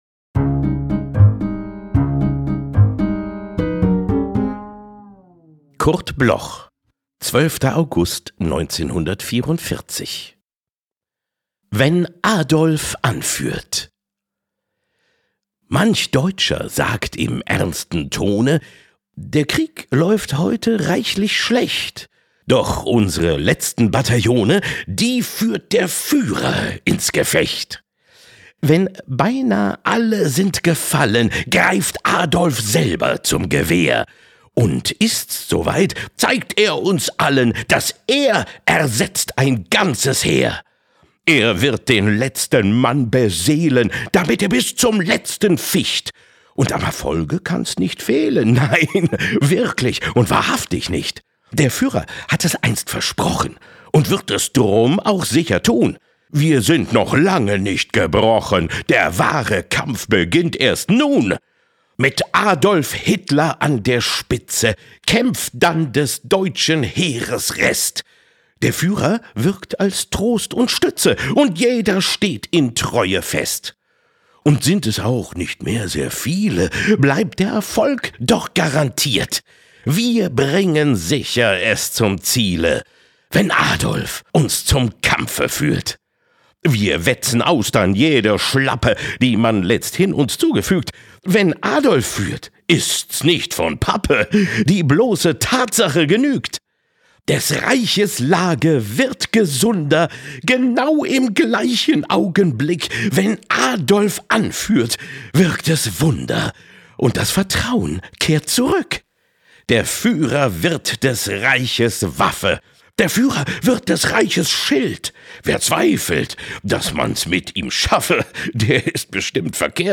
Oliver-Kalkofe-Wenn-Adolf-anfuehrt_mit-Musik_raw.mp3